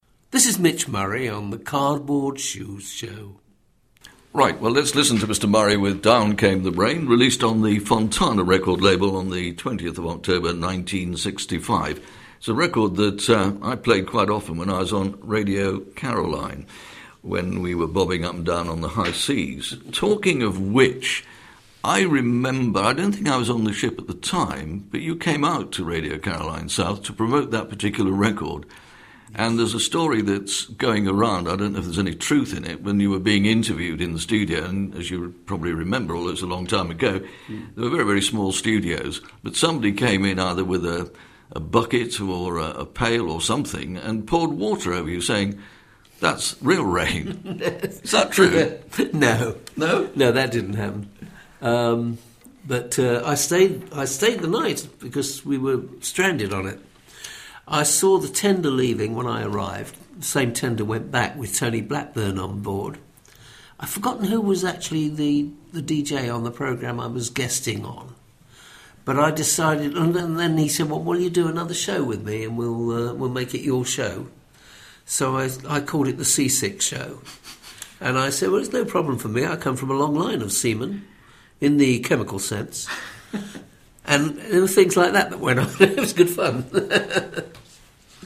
click to hear audio In February 2017 Keith Skues interviewed Mitch Murray for his BBC local radio show and asked him about the visit to Caroline South.